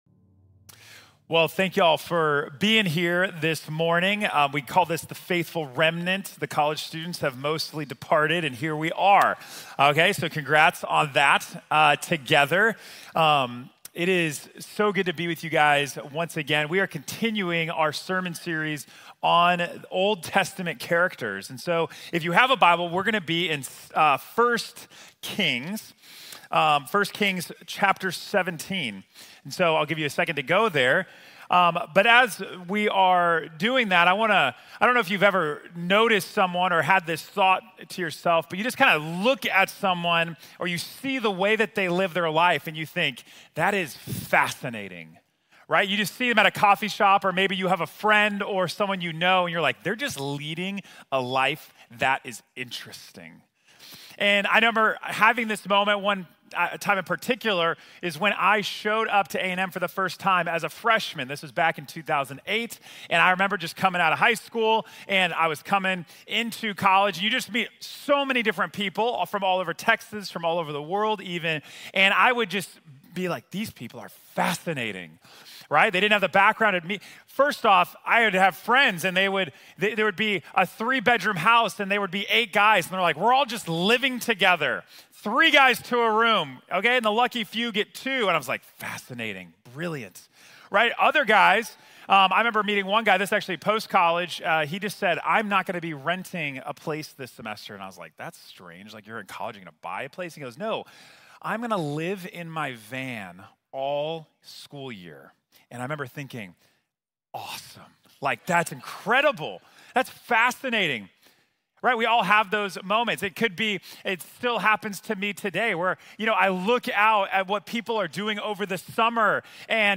How Can I Live An Extraordinary Life | Sermon | Grace Bible Church